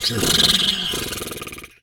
horse_2_breath_03.wav